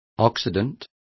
Complete with pronunciation of the translation of occident.